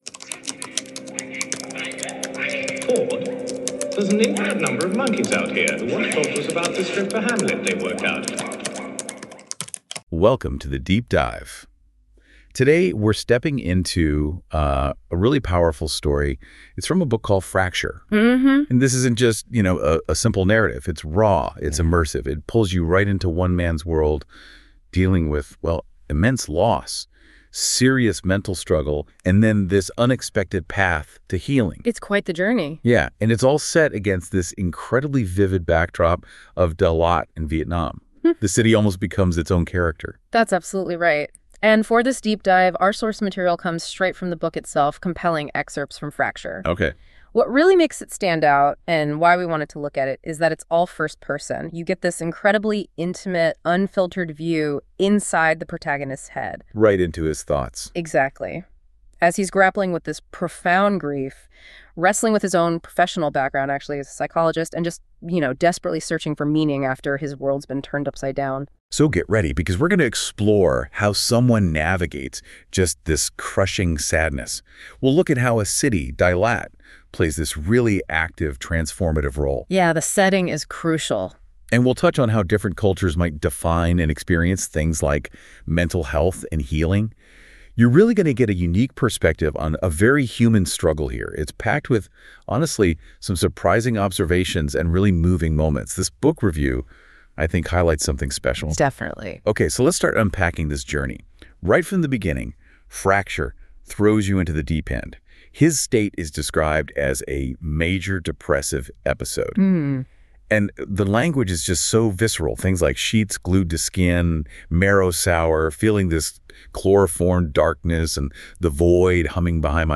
👉 To celebrate the launch, you can: Listen to a special deep-dive podcast episode where the podcast hosts unpack the characters, story, and themes behind Fracture .